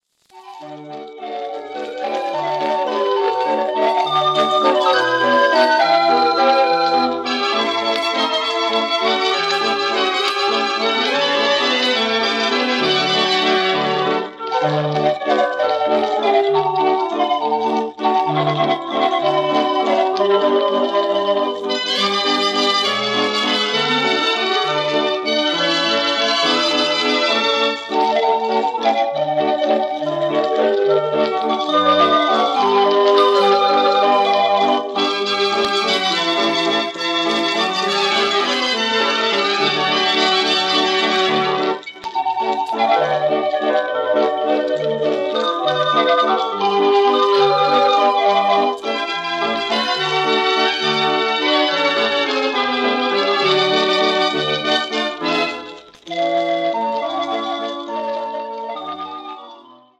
Formaat 78 toerenplaat, 10 inch